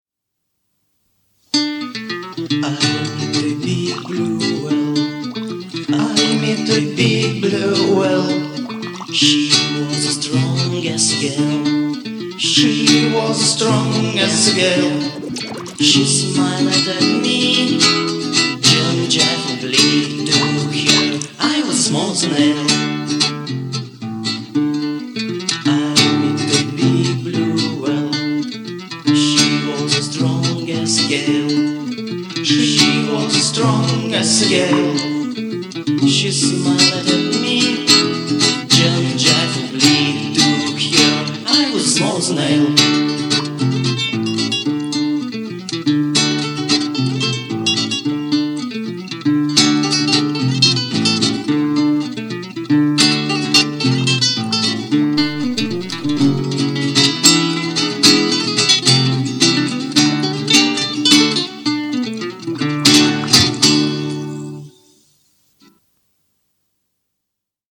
Записали два студийных альбома, ездили на гастроли.